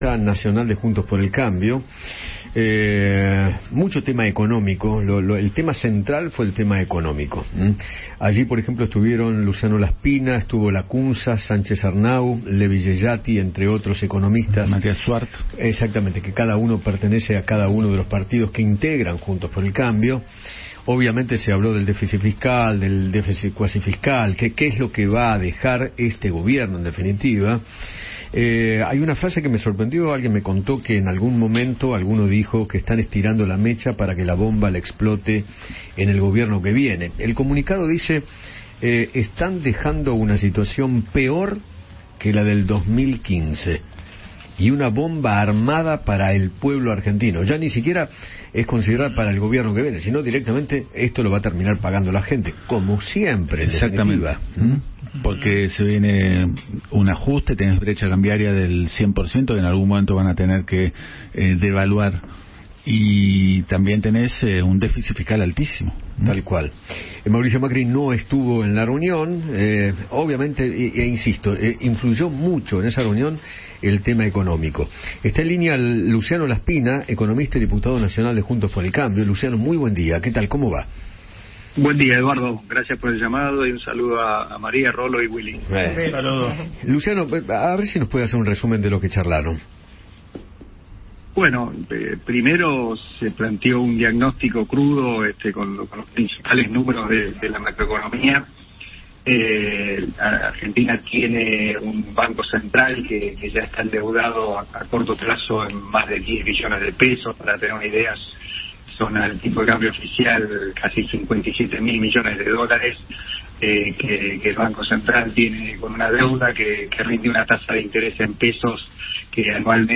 Luciano Laspina, diputado nacional de Juntos por el cambio, dialogó con Eduardo Feinmann sobre la reunión que mantuvo la mesa nacional de su espacio.